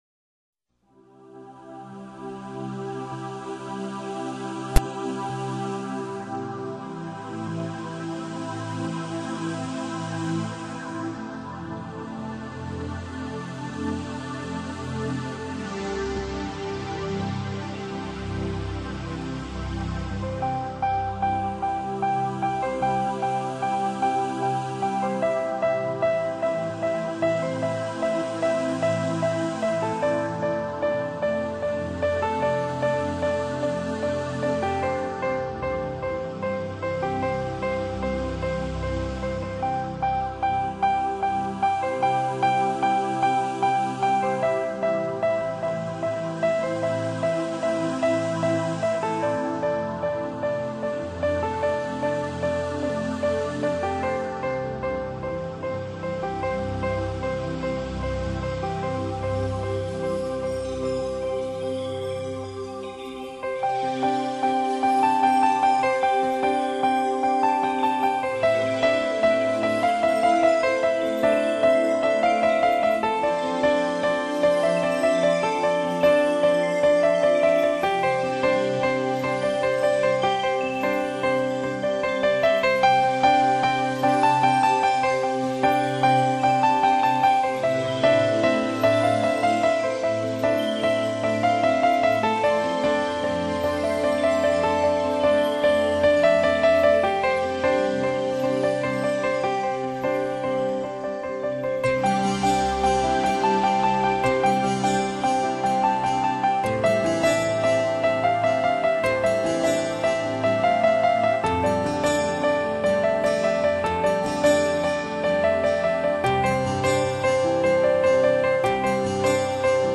音乐类型：新世纪
鋼琴的音色宛如不斷飄落的花瓣，風鈴像風的感動，清幽的笛聲，有山谷的意境。
華麗的電子合成器音色，製造廣大而開闊的空間，有如置身於心靈的花園。